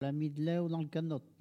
Elle provient de Bouin.
Locution ( parler, expression, langue,... )